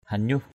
/ha-ɲuh/ (cv.) nyuh v~H (đg.) hỉ mũi = se moucher. hanyuh aia idung hv~H a`% id~/ hỉ mũi = se moucher.